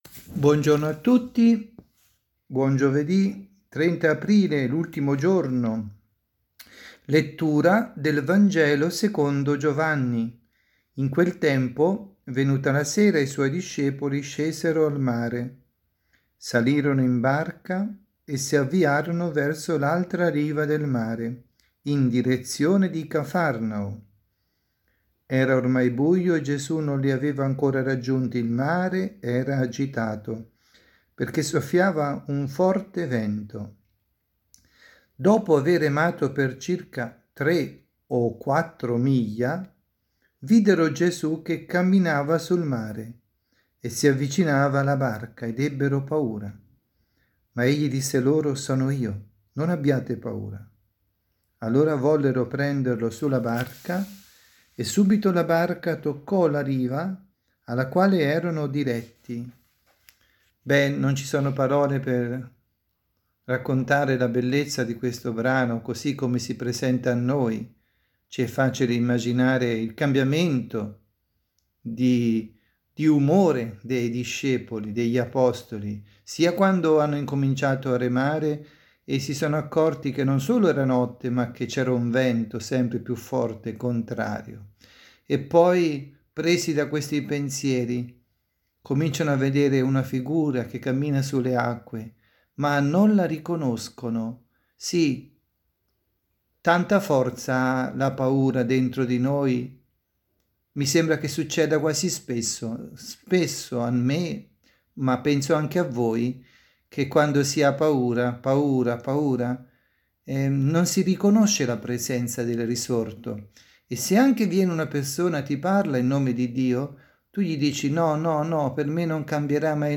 2020-04-30_Giovedi_pMG_preghiera_notturna.mp3